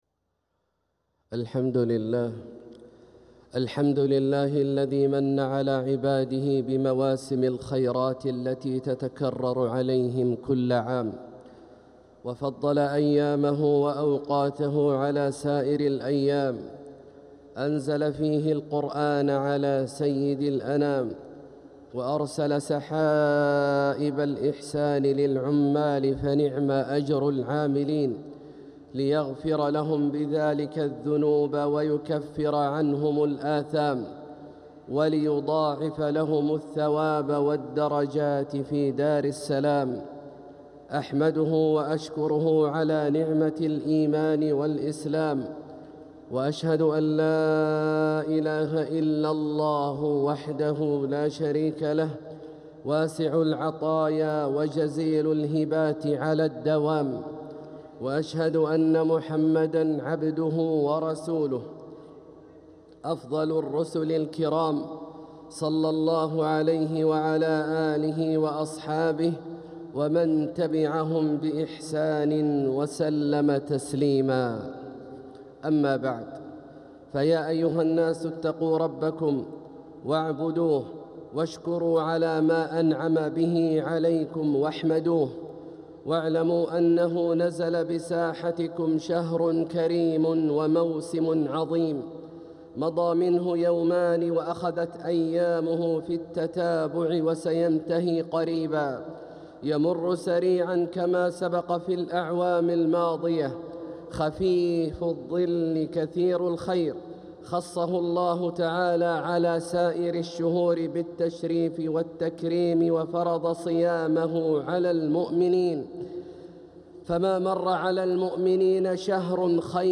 خطبة الجمعة 3-9-1447هـ > خطب الشيخ عبدالله الجهني من الحرم المكي > المزيد - تلاوات عبدالله الجهني